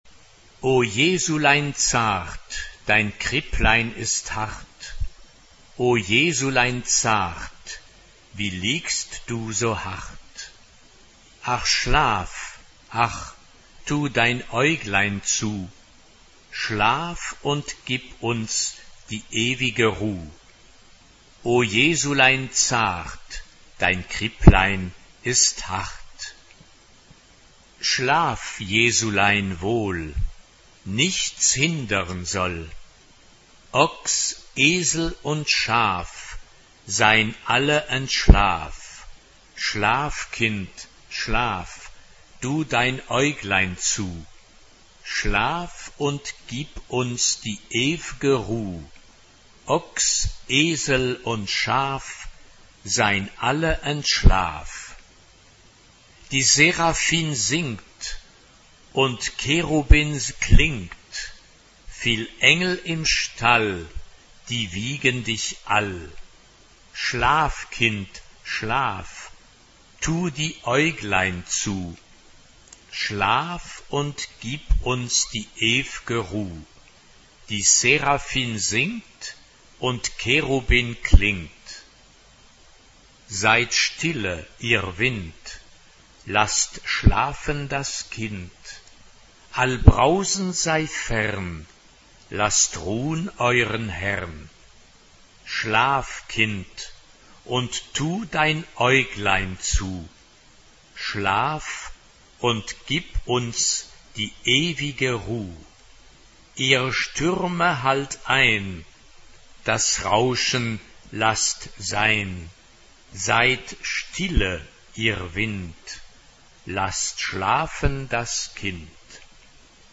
SATB (4 gemischter Chor Stimmen) ; Partitur.
Weihnachtslied. Wiegenlied.
Tonart(en): B-dur